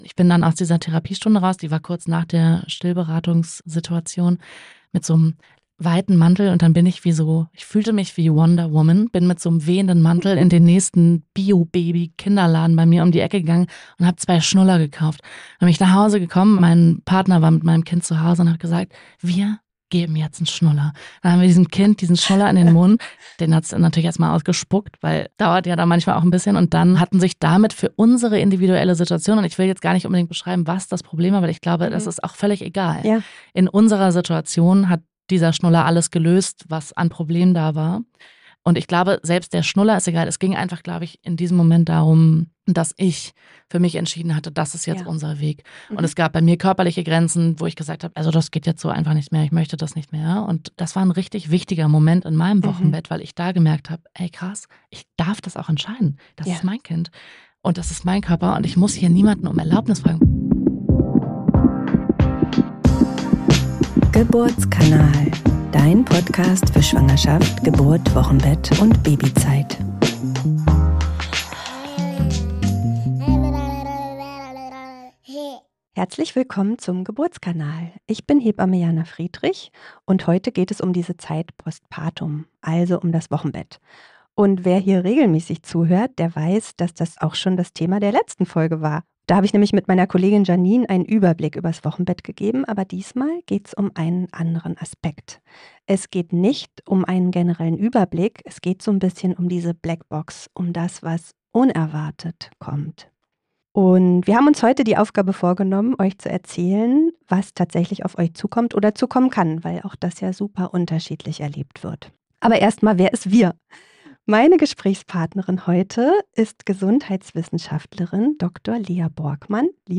live auf dem Podfest Berlin.